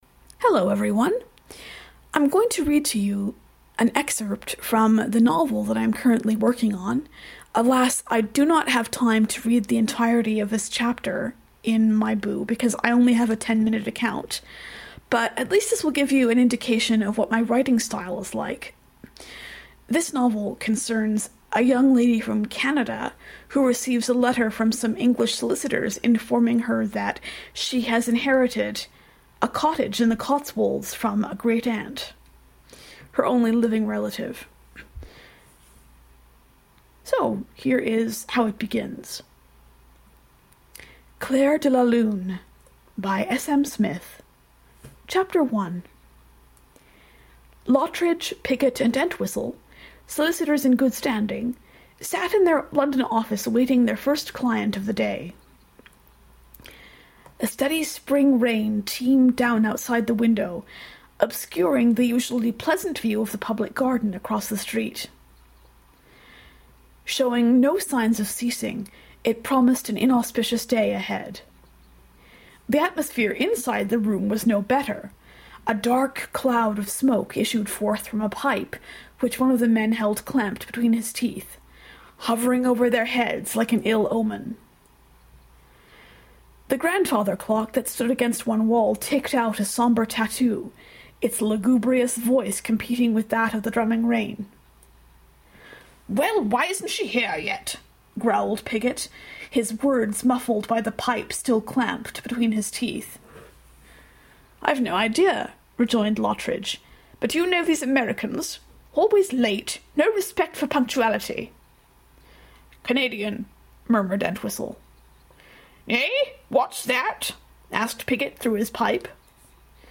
Apologies for the occasional mistakes and pauses in this reading; I had to use my screen reader to listen to each line before reding it out, which was a rather awkward process.